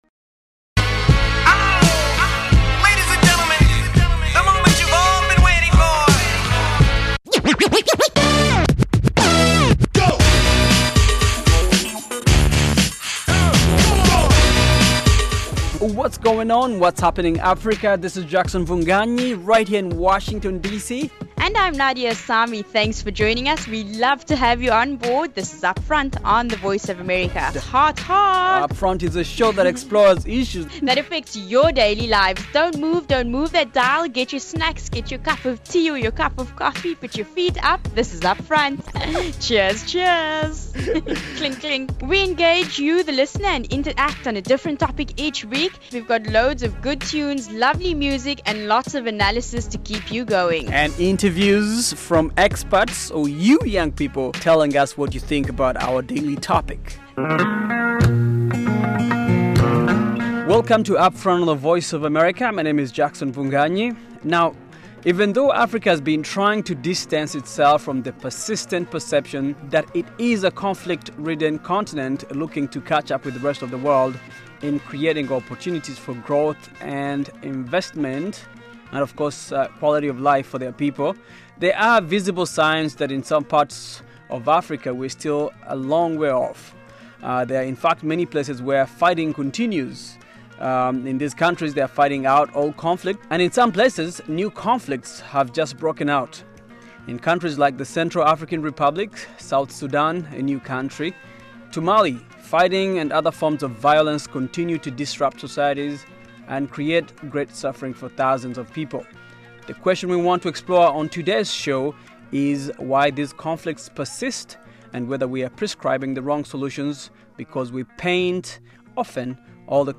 On this fresh, fast-paced show, co-hosts